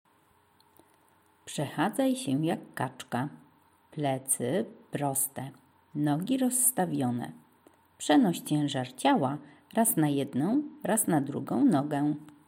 kaczka
kaczka.mp3